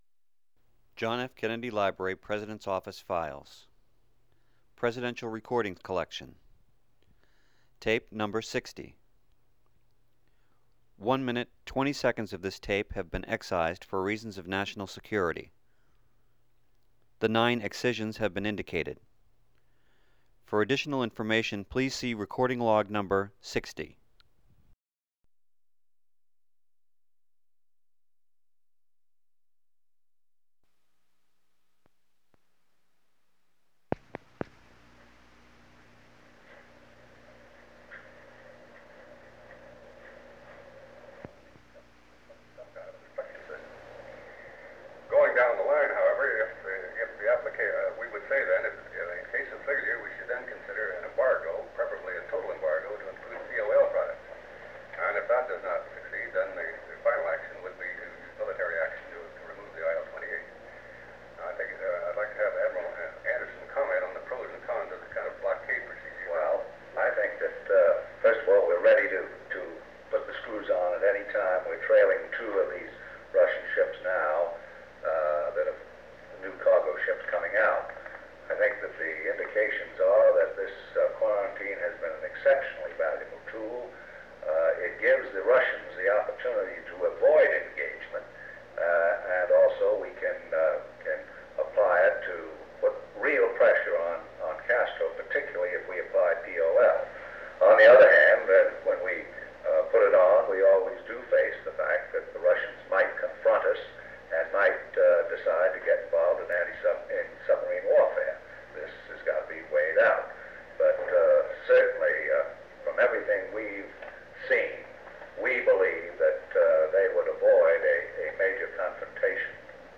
Secret White House Tapes | John F. Kennedy Presidency Meeting with the Joint Chiefs of Staff Rewind 10 seconds Play/Pause Fast-forward 10 seconds 0:00 Download audio Previous Meetings: Tape 121/A57.